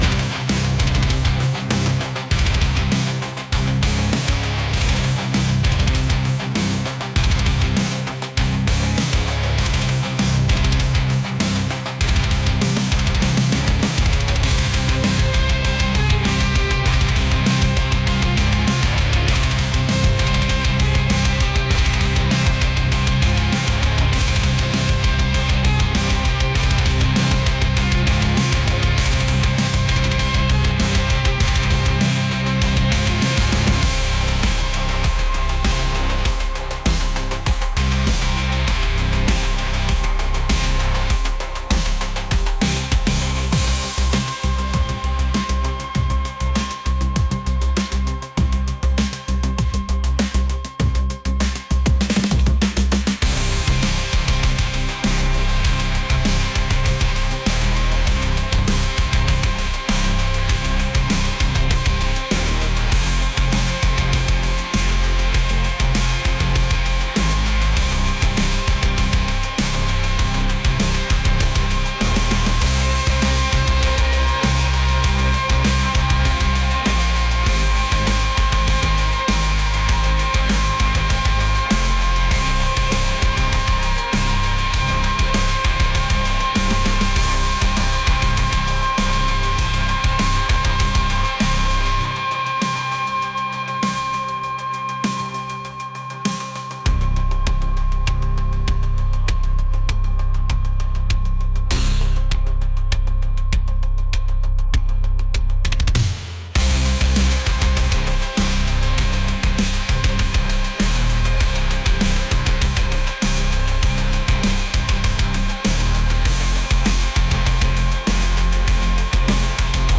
heavy | metal